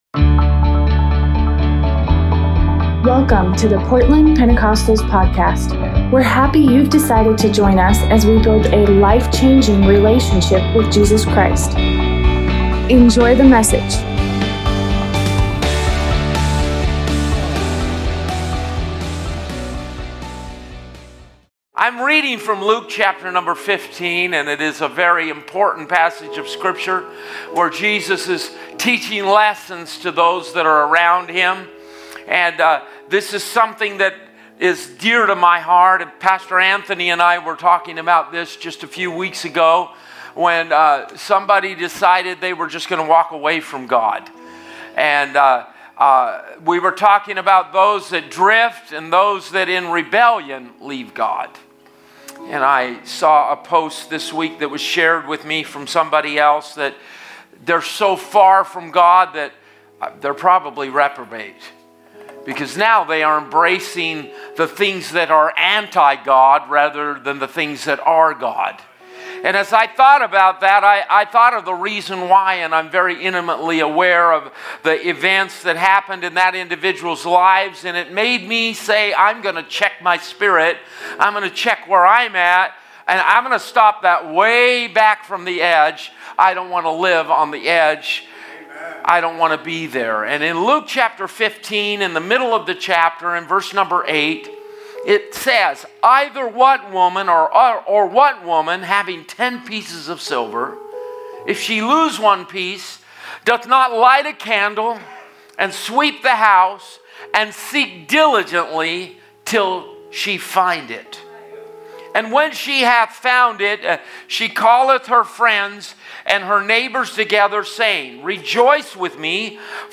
Sunday sermon